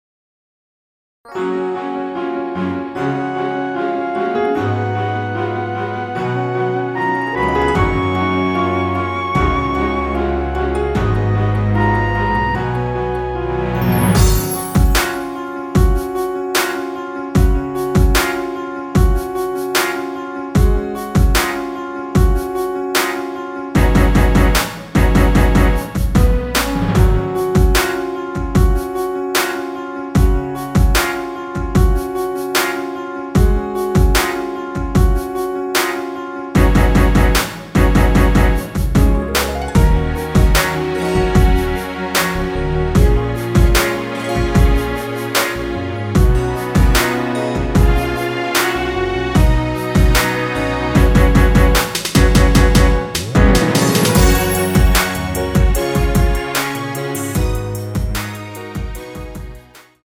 MR입니다.
F#
◈ 곡명 옆 (-1)은 반음 내림, (+1)은 반음 올림 입니다.
앞부분30초, 뒷부분30초씩 편집해서 올려 드리고 있습니다.
중간에 음이 끈어지고 다시 나오는 이유는